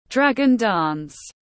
Dragon dance /ˈdræɡ.ən dæns/